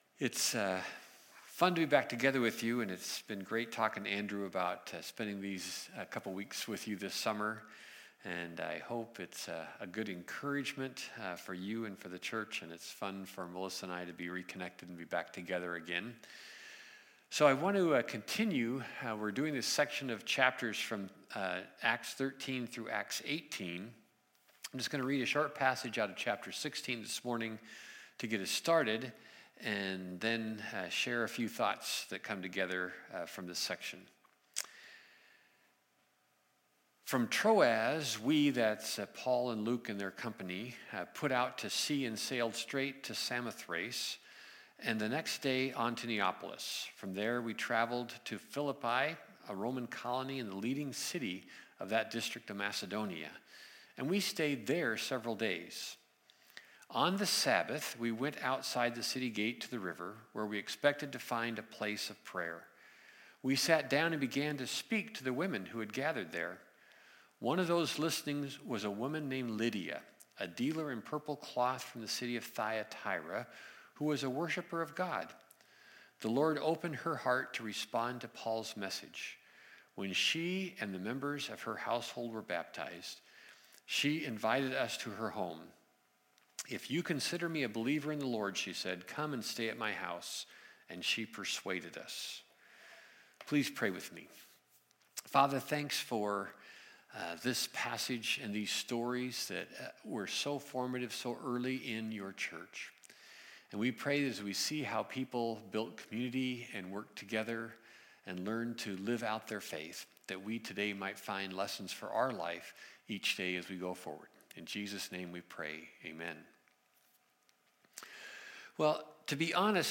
2020-08-16 Sunday Service Guest Speaker
Audio of Sermon